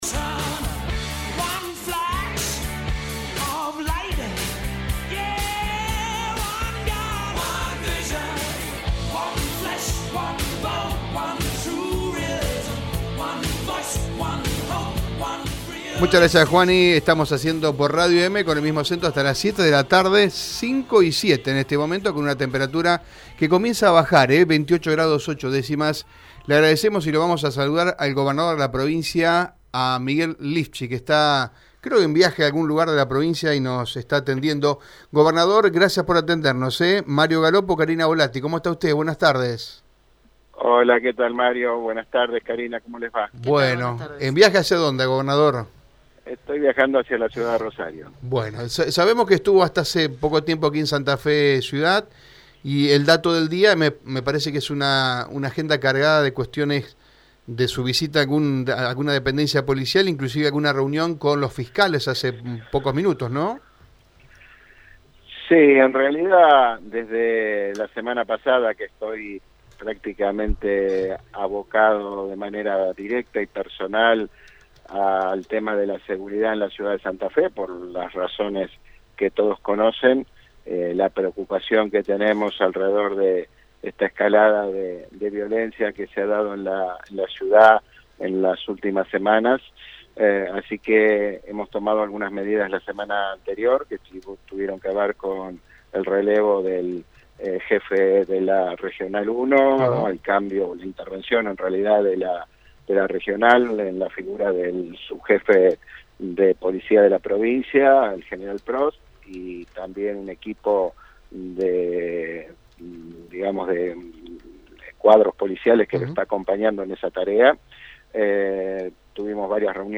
Tras el encuentro, el jefe de Casa Gris mantuvo una entrevista exclusiva con Radio EME y analizó la situación de la seguridad a pocas horas de una nueva marcha contra los crímenes violentos que enlutaron la ciudad.